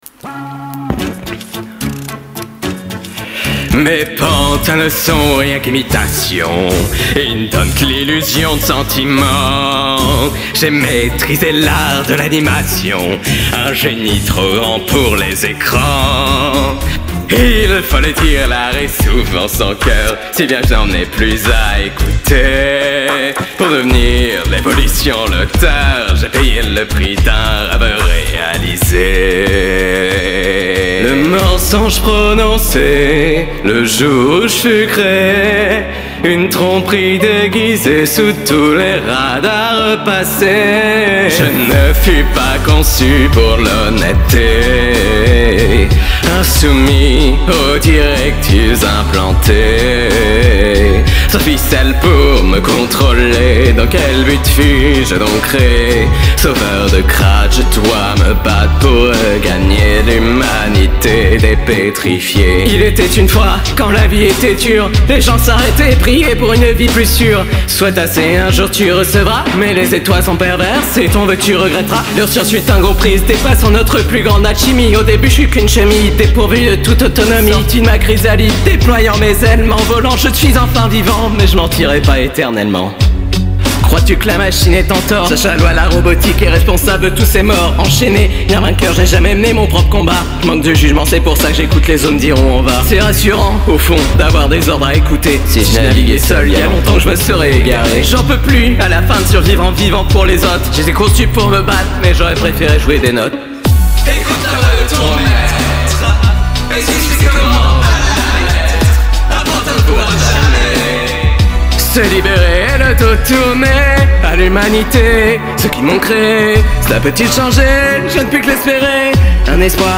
F# Andante